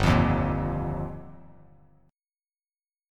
Gm7#5 chord